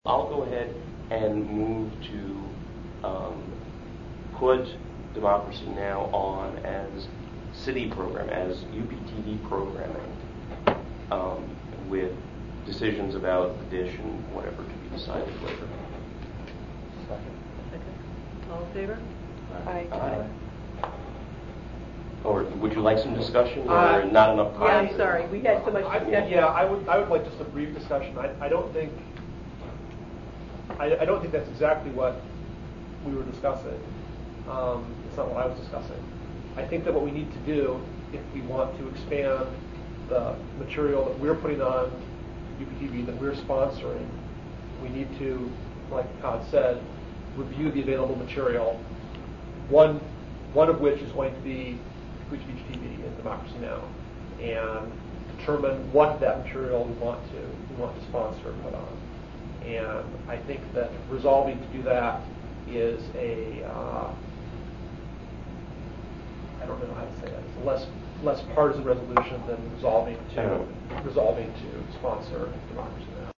Audio clip from the meeting: